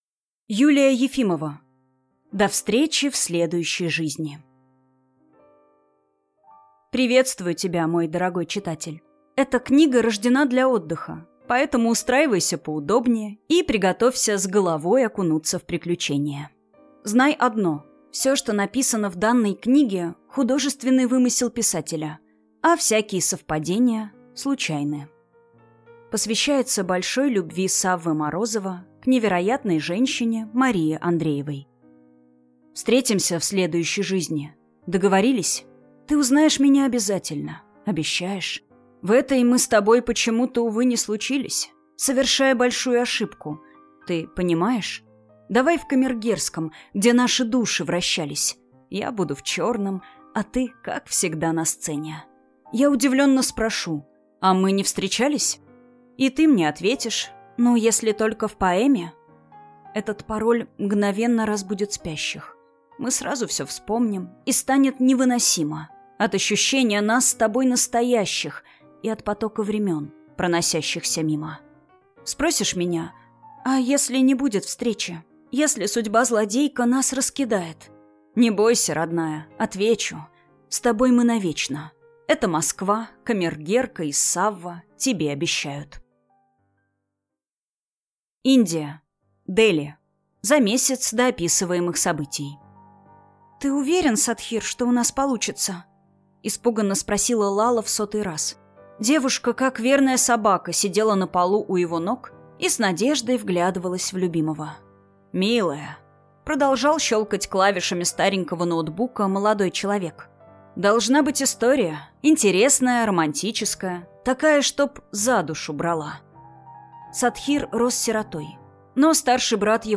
Аудиокнига До встречи в следующей жизни | Библиотека аудиокниг